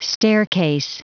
Prononciation du mot staircase en anglais (fichier audio)
Prononciation du mot : staircase